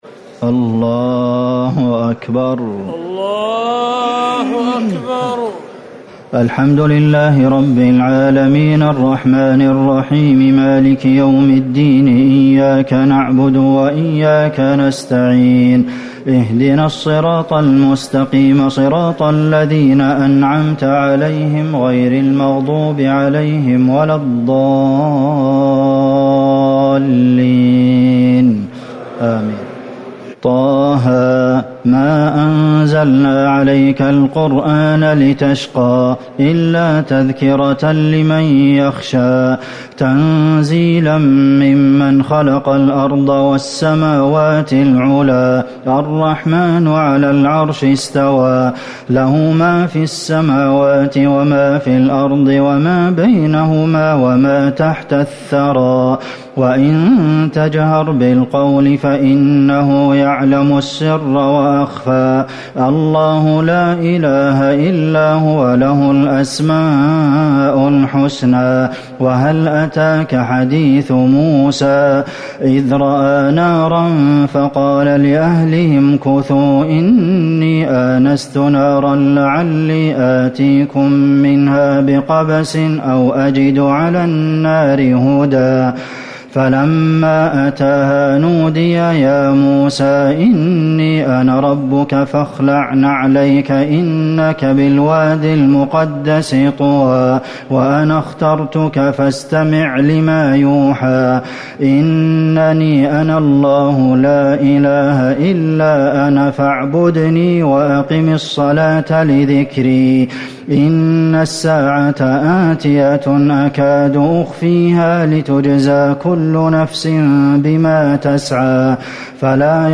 ليلة ١٥ رمضان ١٤٤٠ سورة طه > تراويح الحرم النبوي عام 1440 🕌 > التراويح - تلاوات الحرمين